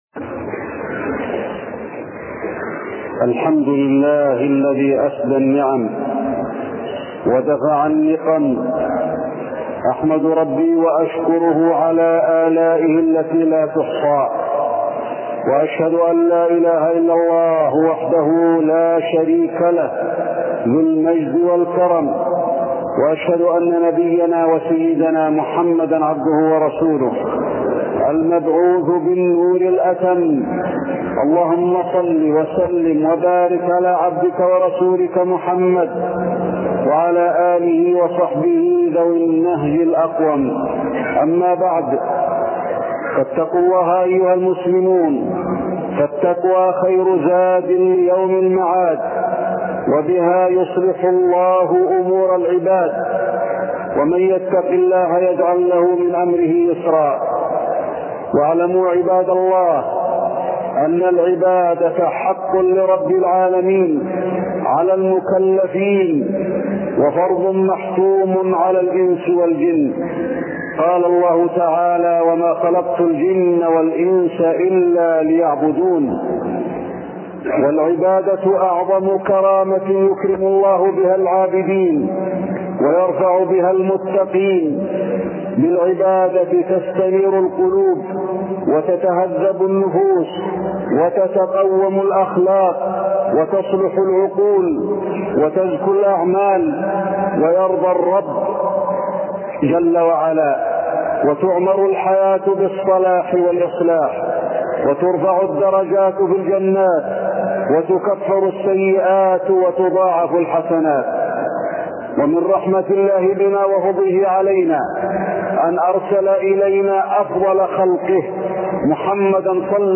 التصنيف: خطب الجمعة